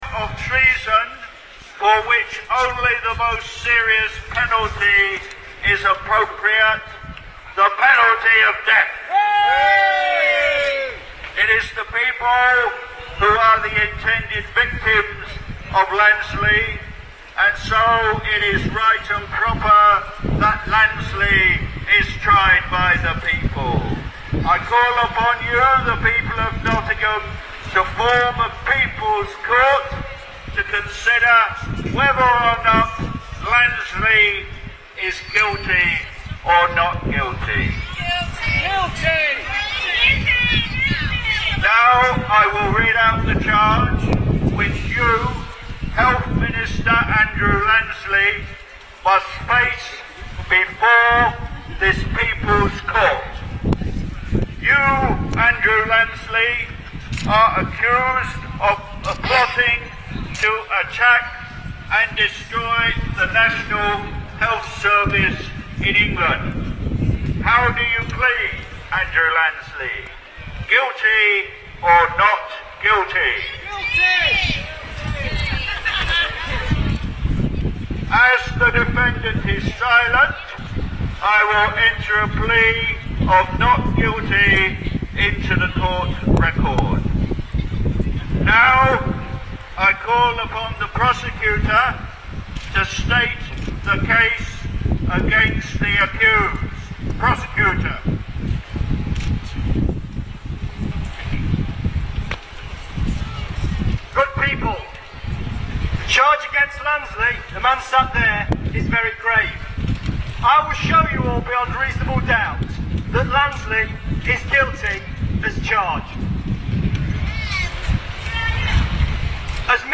Campaigners held a rally at Speakers' Corner, Market Square, where Secretary of State Andrew Lansley was 'put on trial' for his role in promoting the Health and Social Care Bill, which campaigners fear will open the door to the privatisation of the NHS.